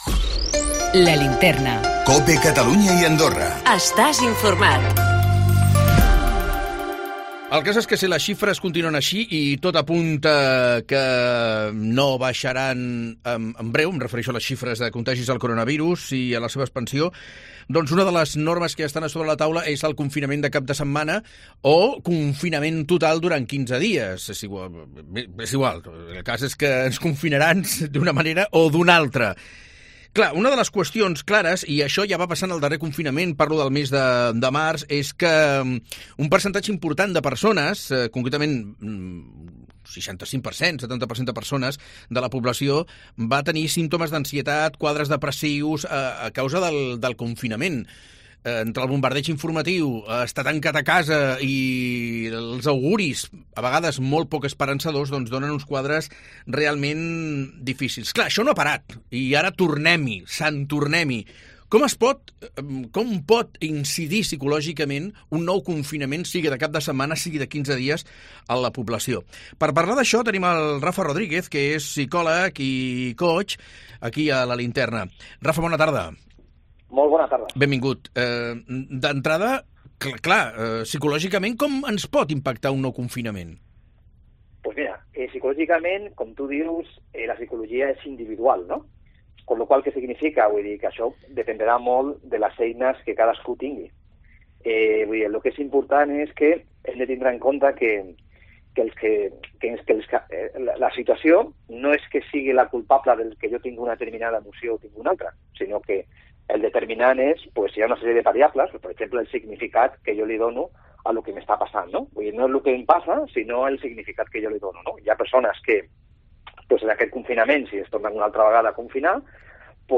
psicòleg i coach